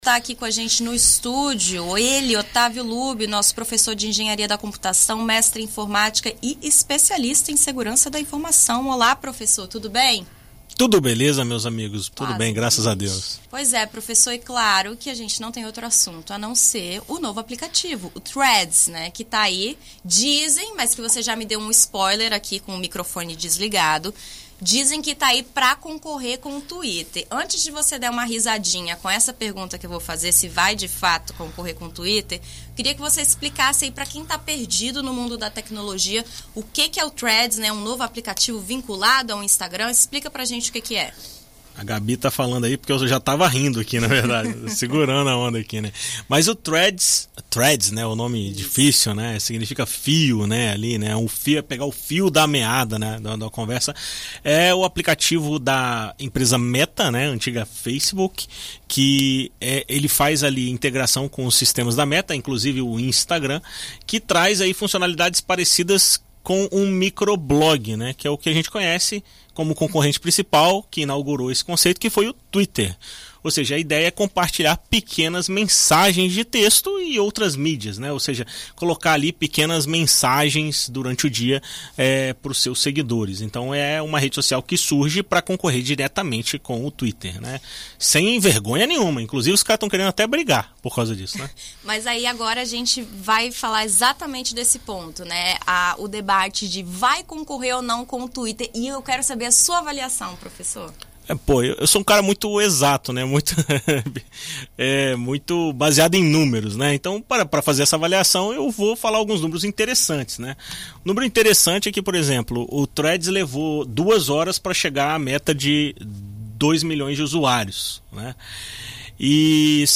Na coluna Tecnoverso da BandNews FM ES desta terça-feira (11)